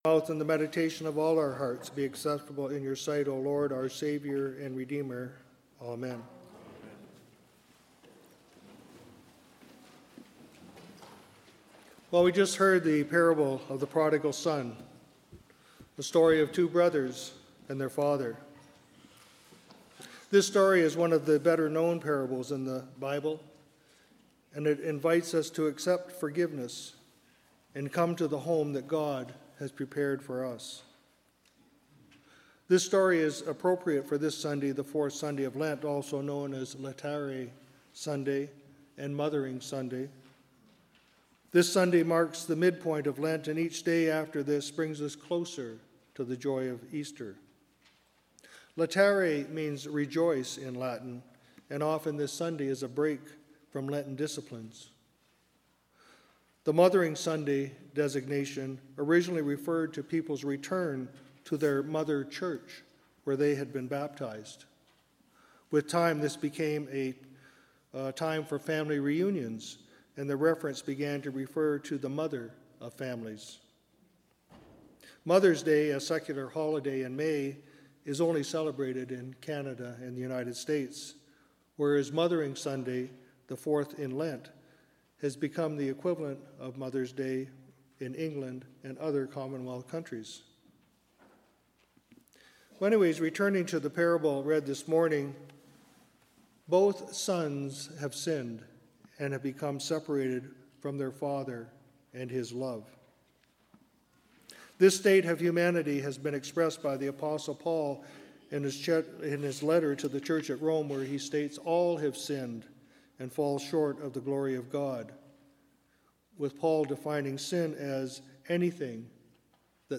Sermons | St. Paul's Anglican Church